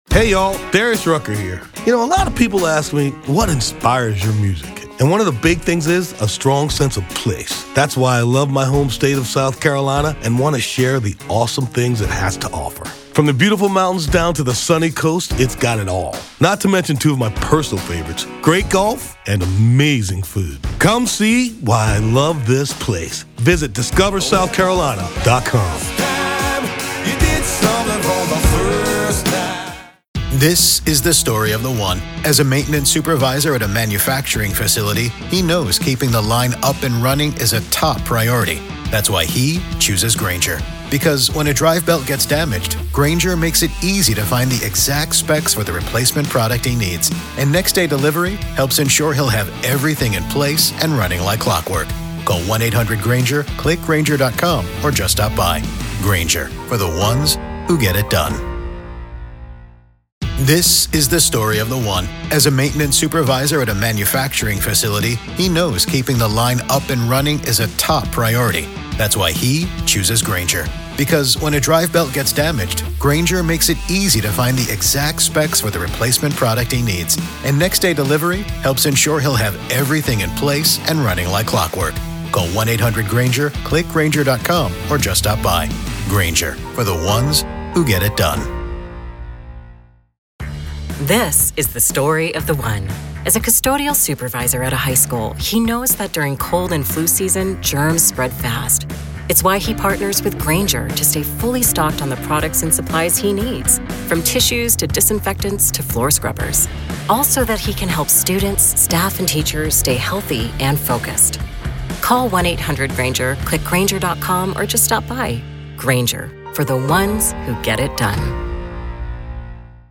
True Crime Today | Daily True Crime News & Interviews / Diddy & Kim Porter's Death, Might We Find A Crime?